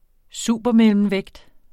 Udtale [ ˈsuˀbʌ- ]